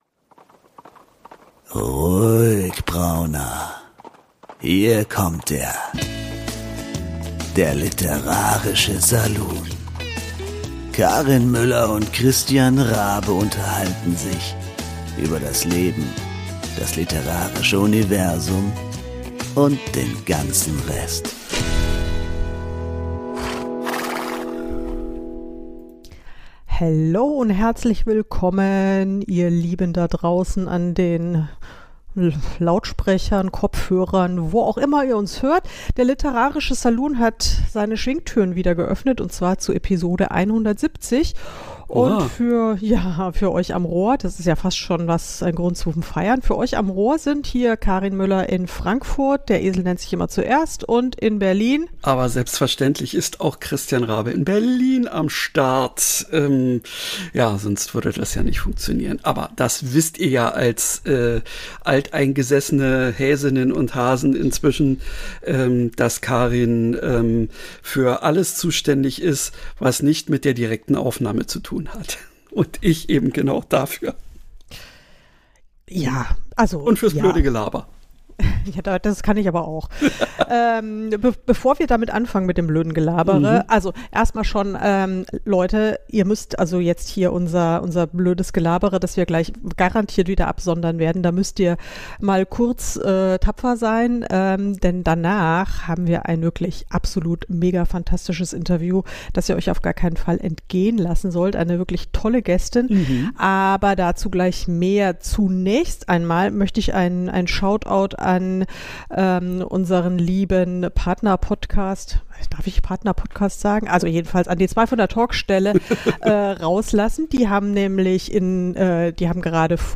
Gibt es Grenzen, die man nicht überschreiten sollte oder darf? Welche Gefühle sind »erlaubt« und welche »verboten«? Es entspinnt sich eine tiefe Diskussion und am Ende gibt’s sogar noch die Antwort auf die Frage, ob eine Paartherapie für die Hosts nötig ist oder nicht!